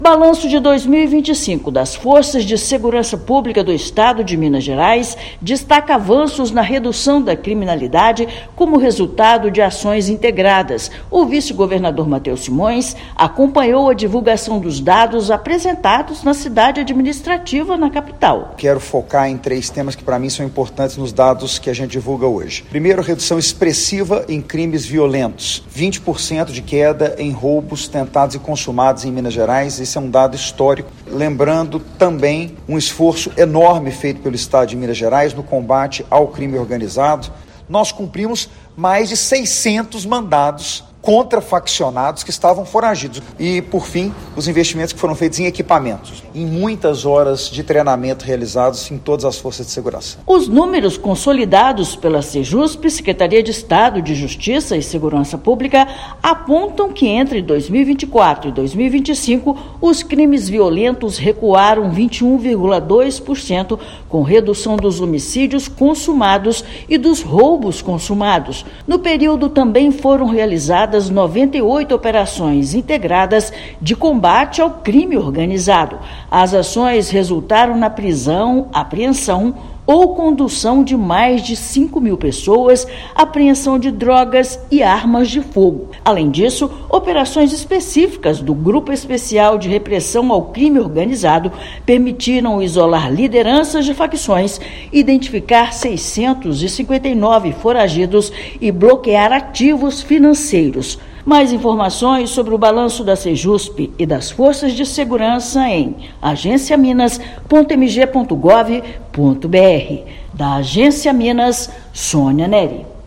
[RÁDIO] Minas Gerais alcança avanços históricos em 2025 com política integrada de segurança pública
Ações coordenadas reduzem crimes, ampliam a proteção ambiental e fortalecem a defesa social. Ouça matéria de rádio.